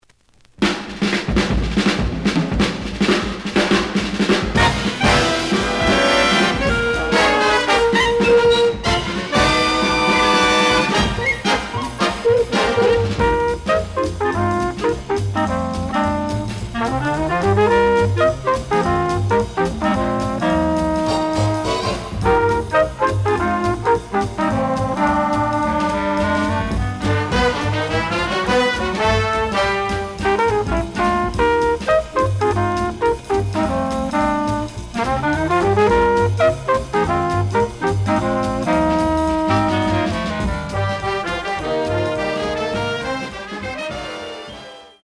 The audio sample is recorded from the actual item.
●Genre: Bop